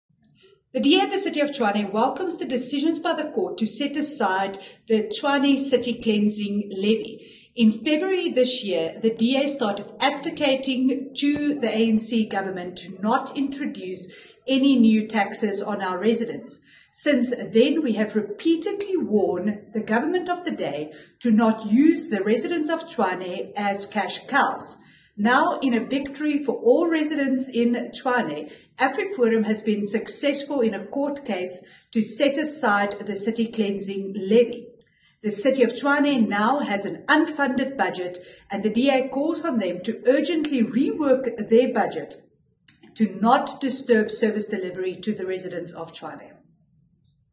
Note to Editors: Please find an English soundbite by Cllr Jacqui Uys